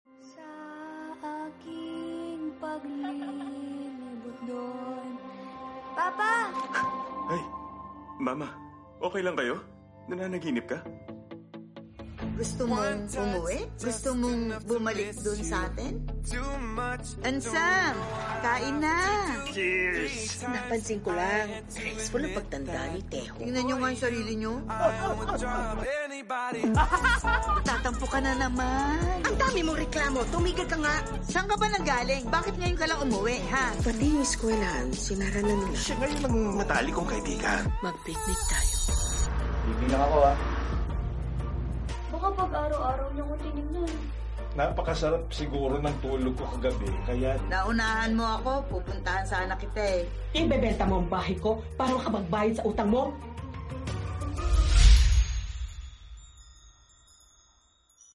Now in Filipino, dubbed by Ms. Nova Villa, Ms. Ces Quesada, and Mr. Bodjie Pascua — joined by JM Ibarra and Fyang Smith in their voice acting debut. Prepare your hearts — PICNIC opens in cinemas nationwide this May 7.